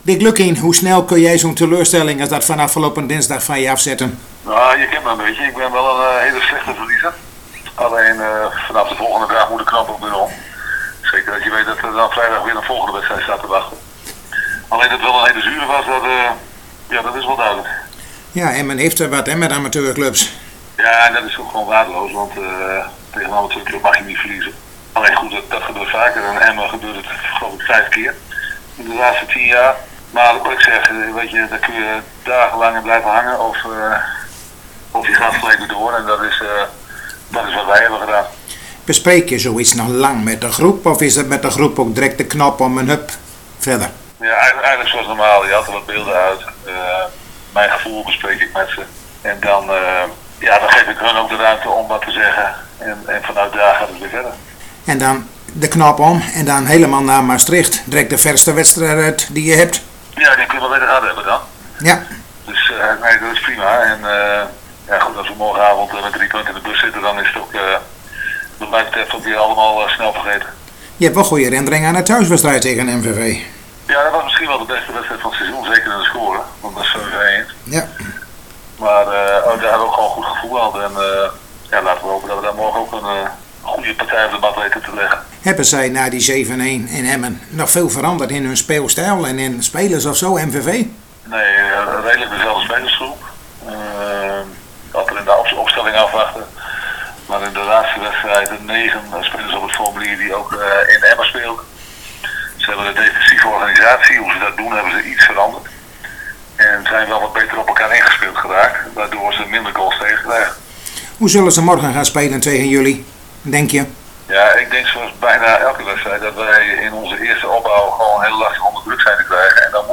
In gesprek